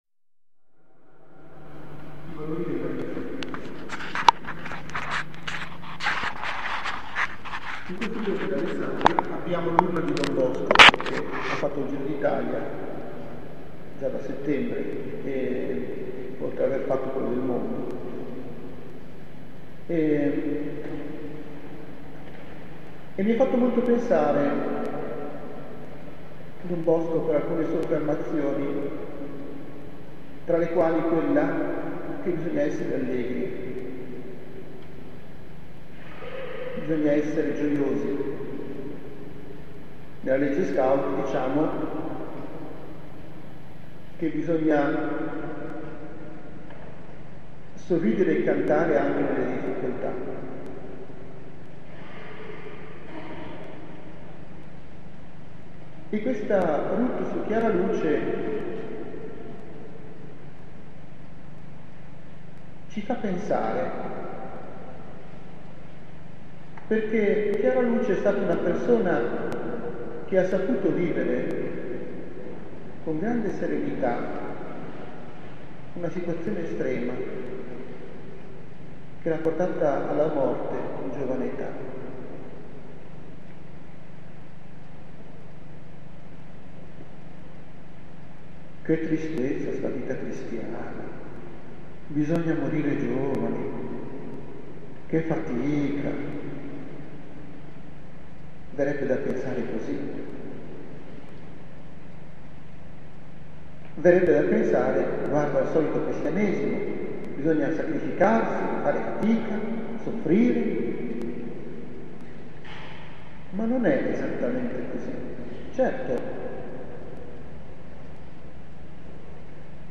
Messa Conclusiva celebrata da S.E. Mons. Guido Gallese vescovo di Alessandria.
RdN 2013 omelia finale.mp3